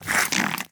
Minecraft Version Minecraft Version snapshot Latest Release | Latest Snapshot snapshot / assets / minecraft / sounds / mob / fox / aggro6.ogg Compare With Compare With Latest Release | Latest Snapshot